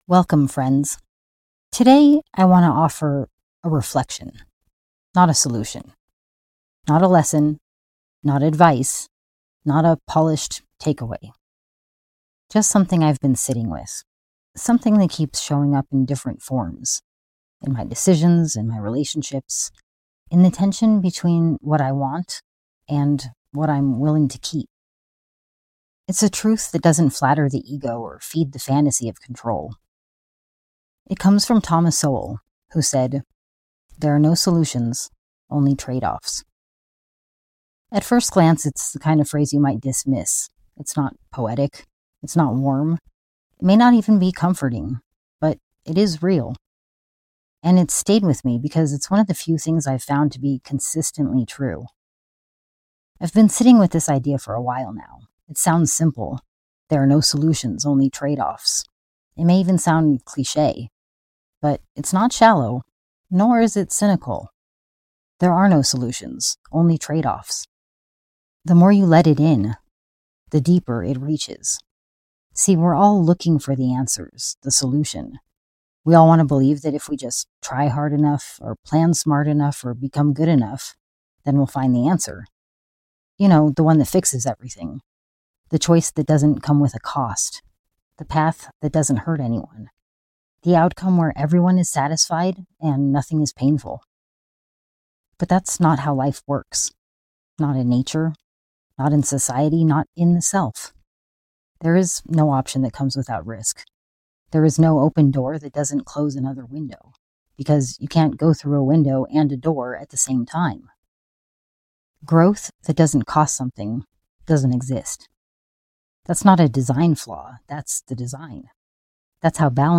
no-solutions_denoised.mp3